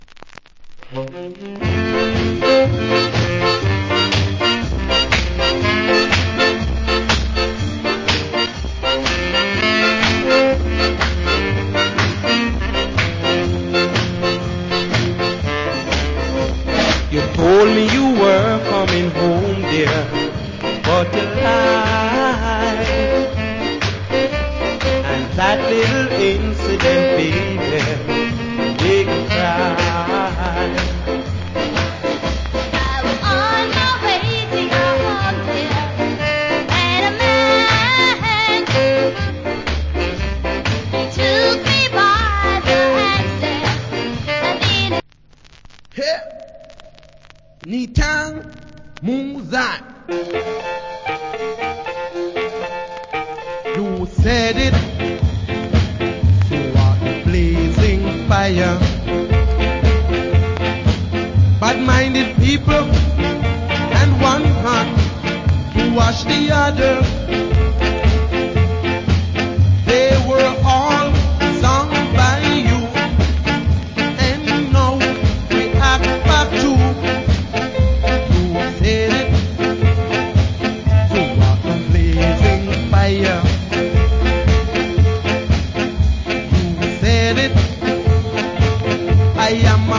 Wicked Duet Ska Vocal.